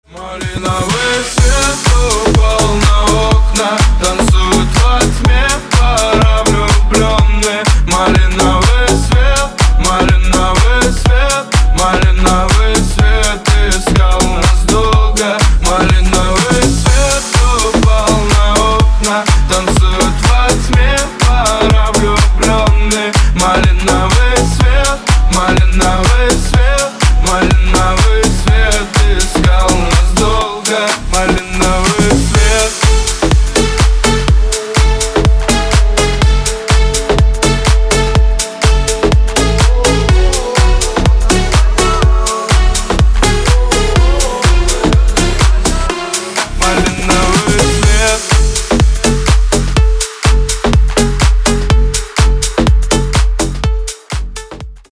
мужской вокал
громкие
dance
Electronic
EDM
Club House
электронная музыка